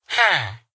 minecraft / sounds / mob / villager / yes1.ogg